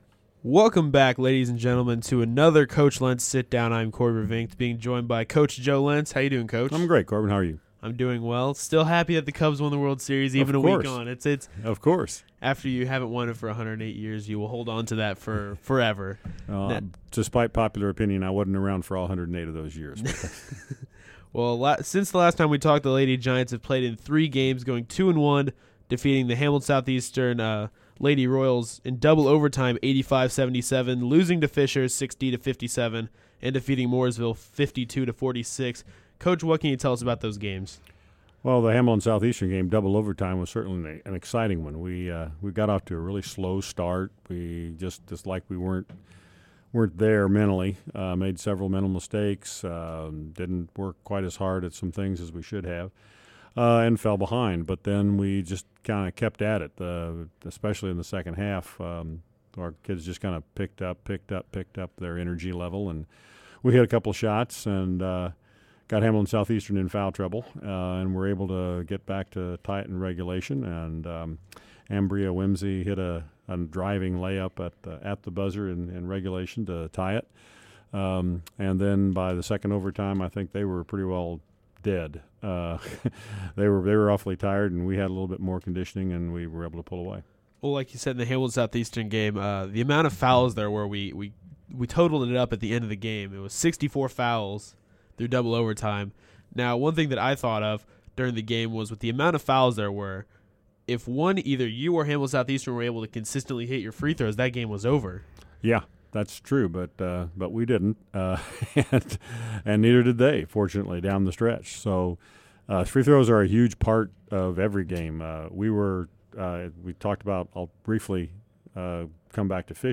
Interview Week #2